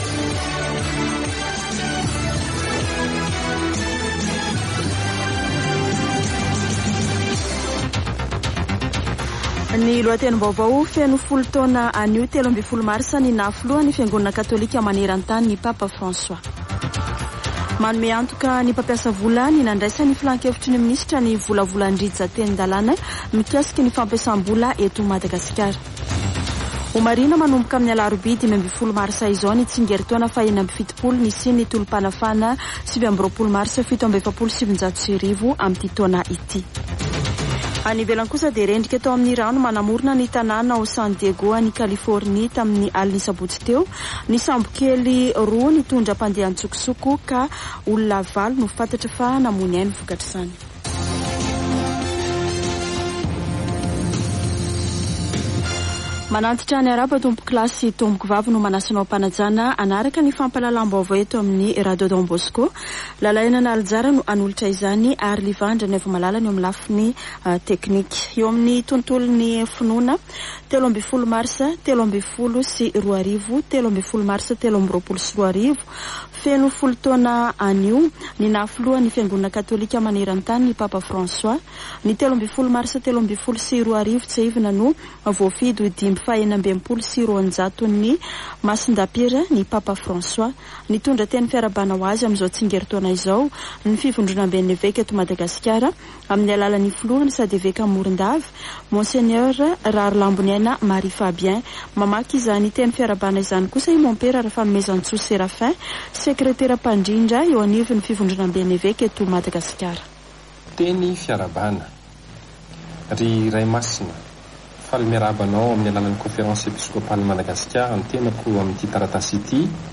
[Vaovao antoandro] Alatsinainy 13 marsa 2023